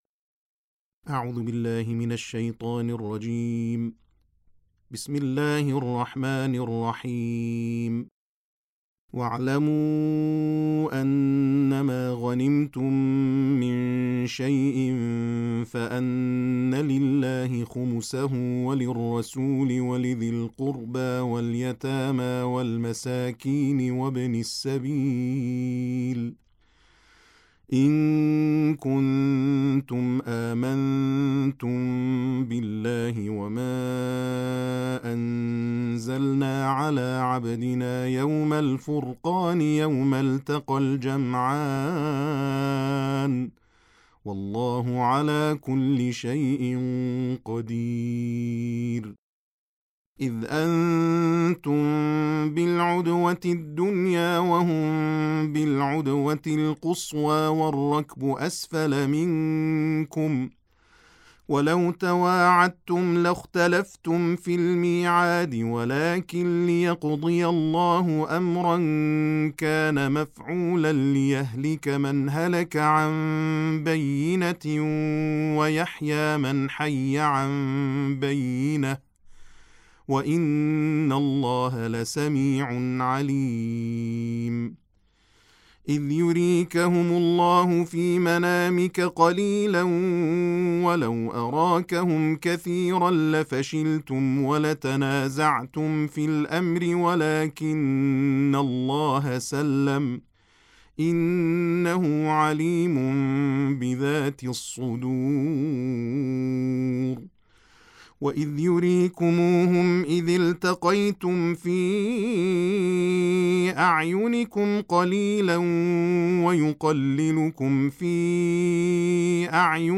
تلاوت جزء دهم